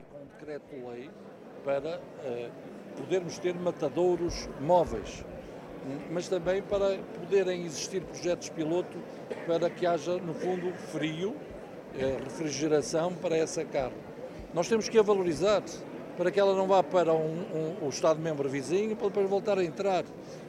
O governante, que esteve presente na Feira da Caça de Macedo de Cavaleiros, defende soluções que permitam dar valor económico à carne de caça e evitar a sua saída para outros países: